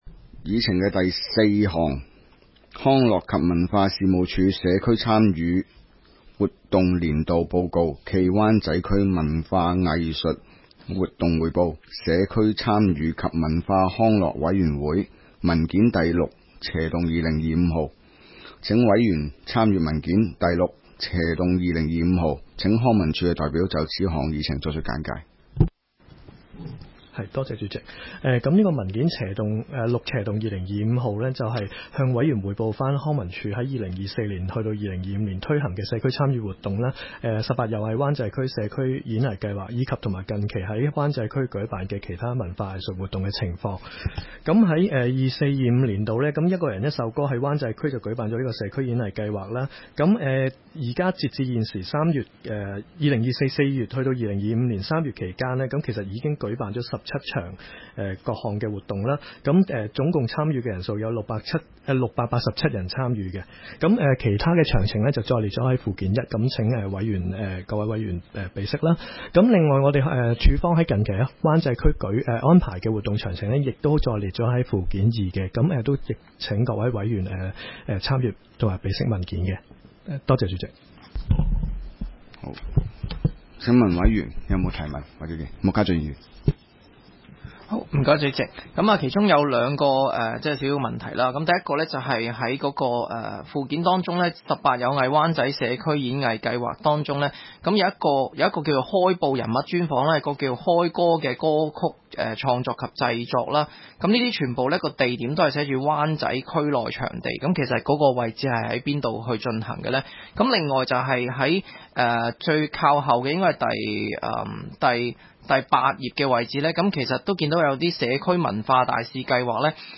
湾仔区议会 - 委员会会议的录音记录
工作小组会议的录音记录